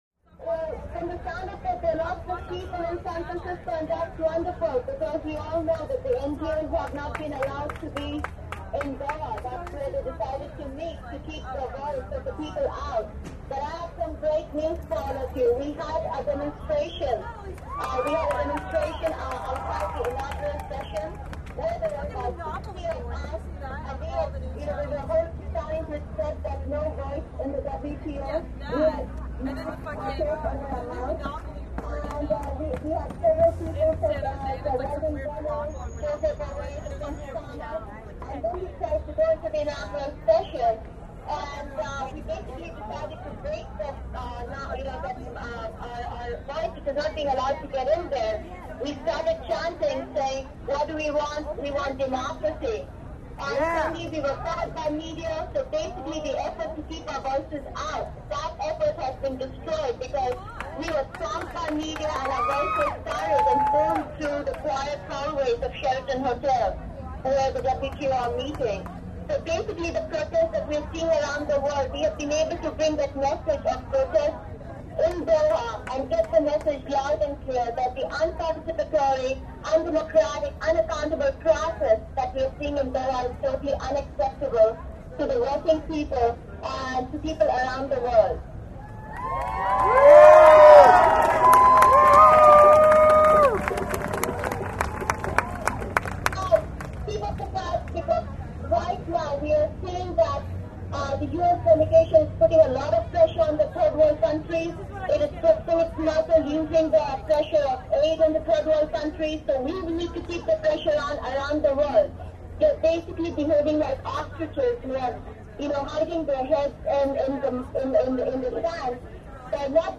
audio from Anti-WTO Rally, San Francisco
(recorded to minidisc with binaural mics)
cellphone_from_qatar.mp3